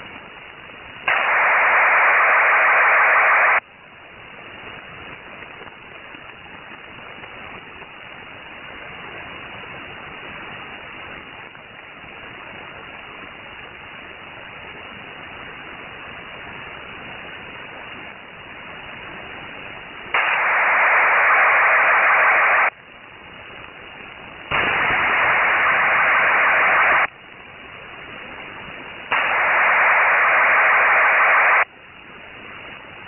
Rockwell Collins Commercial airborne waveform with coded 8-PSK data bursts (audio file edited to reduce background noise!)
RC_comm_airb_wavefrm.WAV